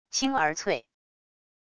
清而脆wav音频